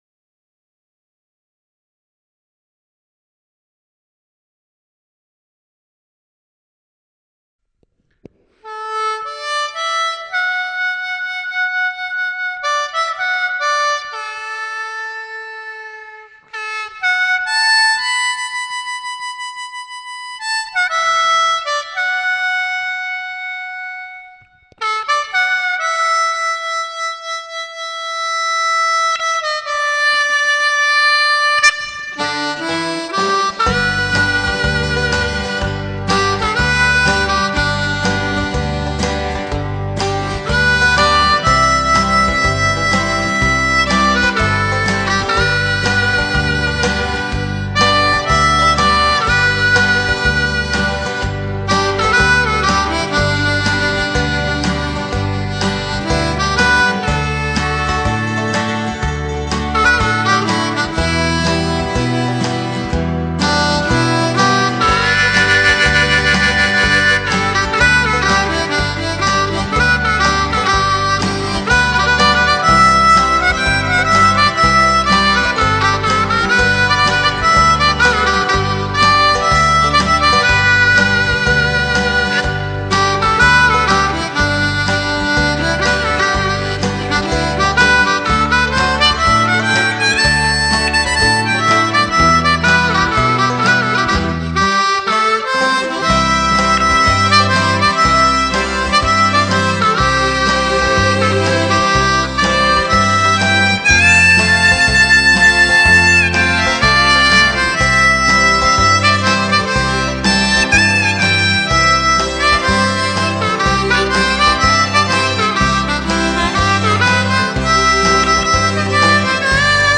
Manji in C